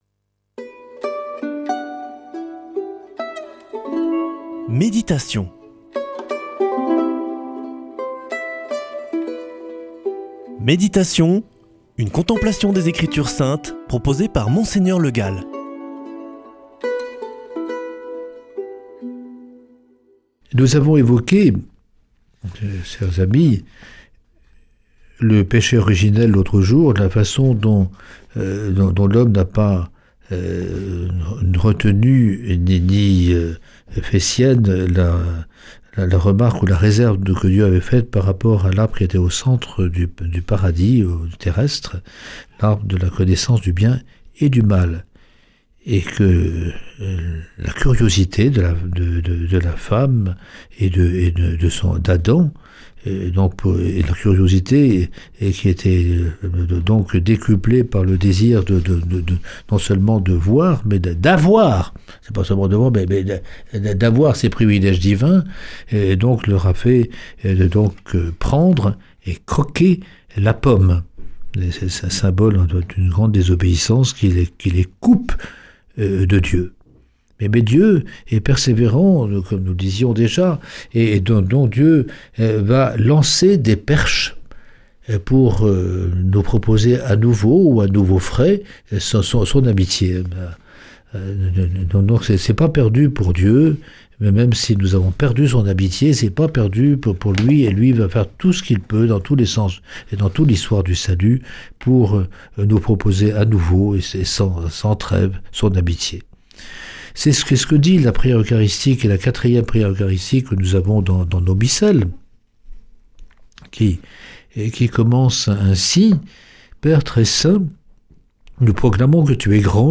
Méditation avec Mgr Le Gall
Une émission présentée par